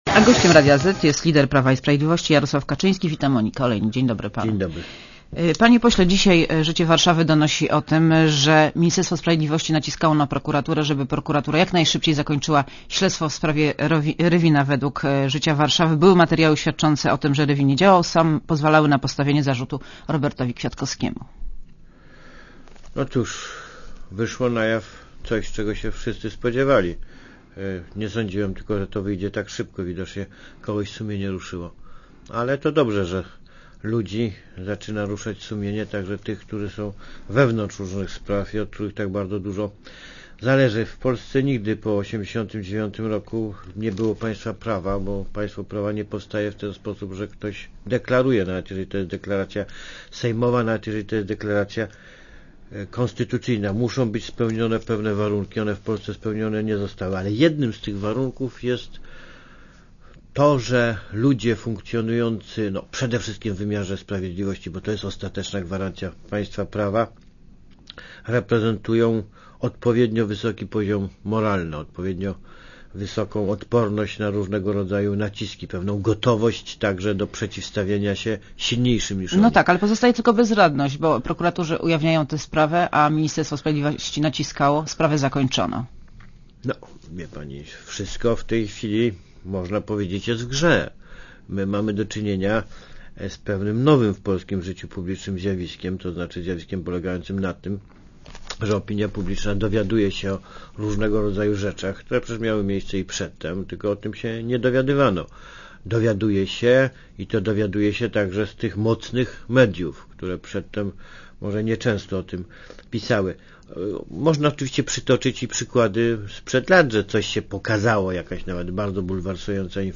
Zmiana na scenie politycznej potrzebna jest od zaraz i nie można na nią czekać do wyborów - powiedział Jarosław Kaczyński, lider PiS w rozmowie z Moniką Olejnik w Radiu Zet.